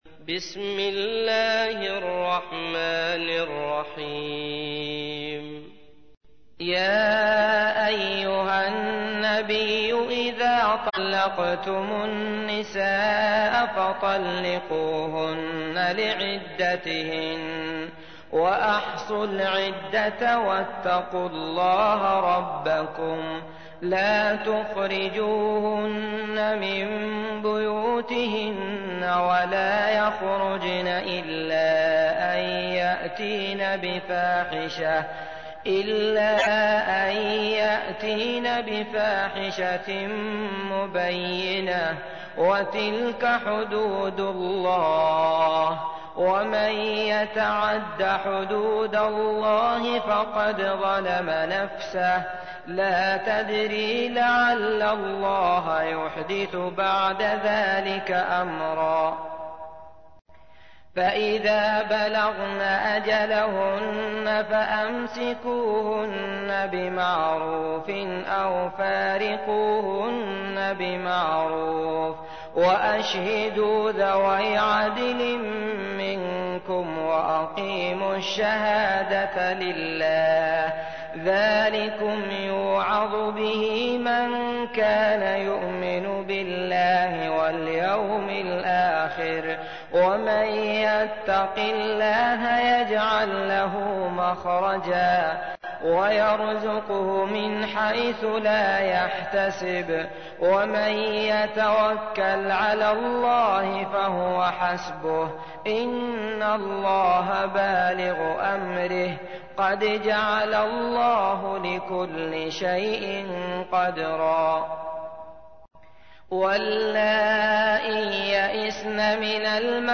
تحميل : 65. سورة الطلاق / القارئ عبد الله المطرود / القرآن الكريم / موقع يا حسين